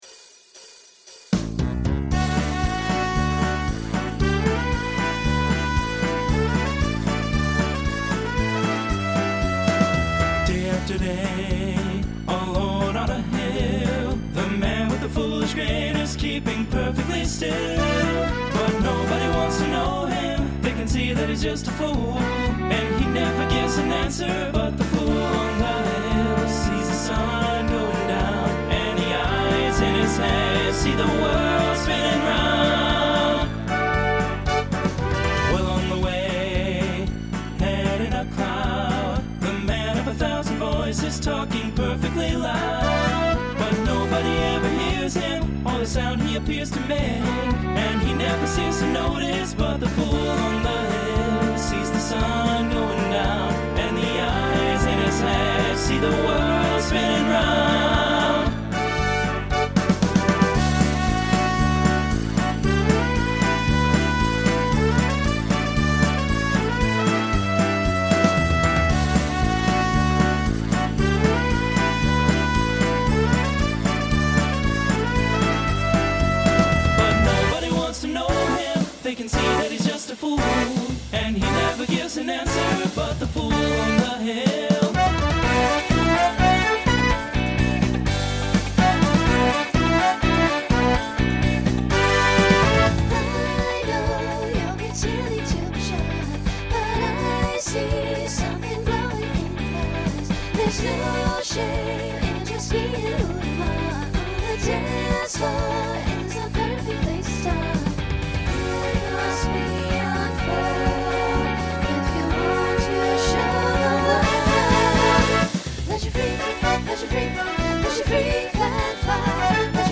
Disco , Pop/Dance , Rock
Voicing Mixed